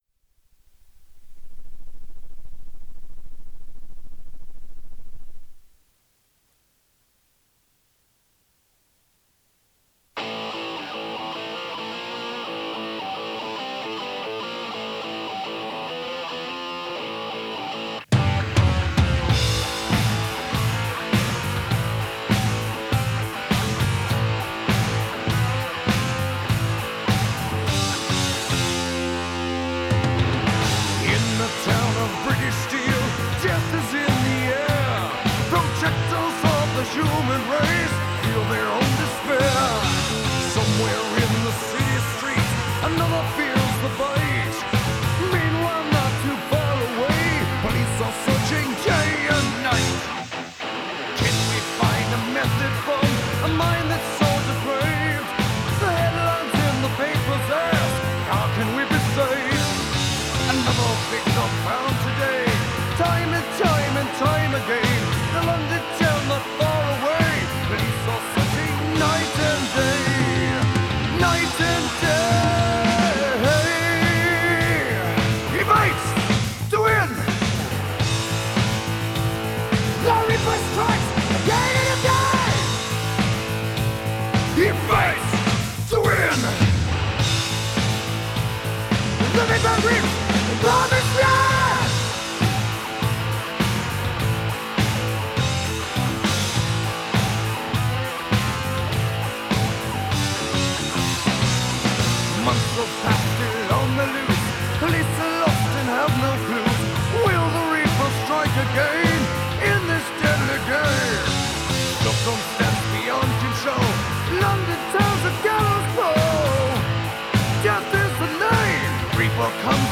the content here is essentially edited from two "Jam sessions:"
Both were edited in my linear fashion. i.e. just but cutting out bits and hoping that what's left sticks together ok.